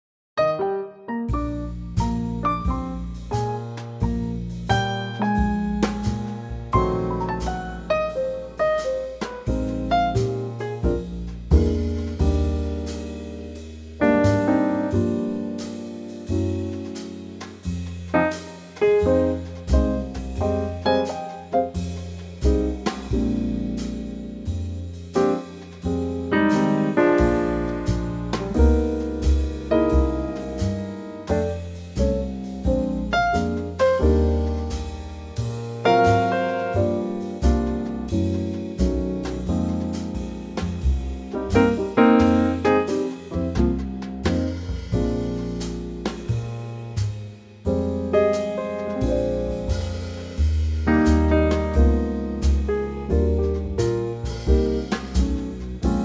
key - Eb - vocal range - Bb to D
Here's a lovely Trio arrangement.
Same arrangement as M3210 except a full tone lower.